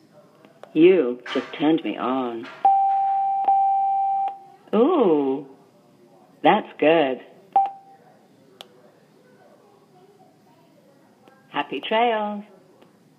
Here is a sound bite from the voice on The Beacon bluetooth speaker: